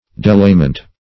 delayment - definition of delayment - synonyms, pronunciation, spelling from Free Dictionary
delayment - definition of delayment - synonyms, pronunciation, spelling from Free Dictionary Search Result for " delayment" : The Collaborative International Dictionary of English v.0.48: Delayment \De*lay"ment\, n. Hindrance.
delayment.mp3